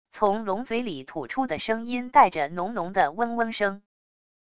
从龙嘴里吐出的声音带着浓浓的嗡嗡声wav音频